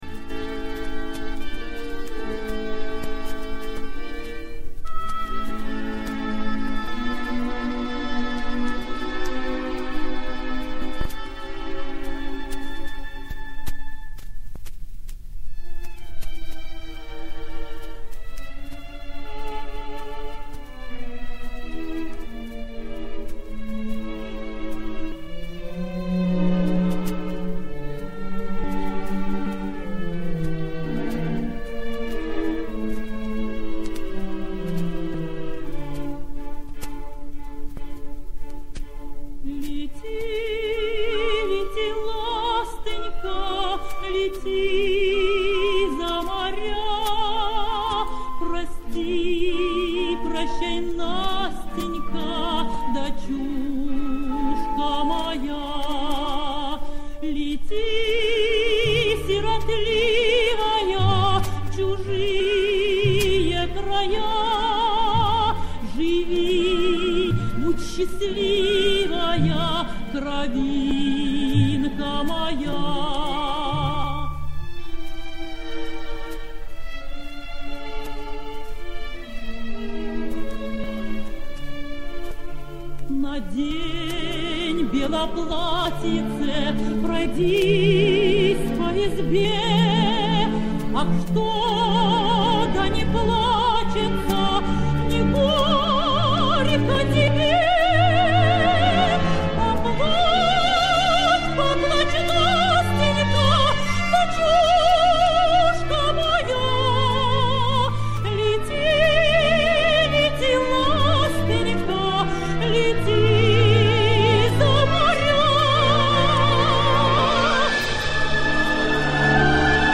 С пластинок к 30-летию Победы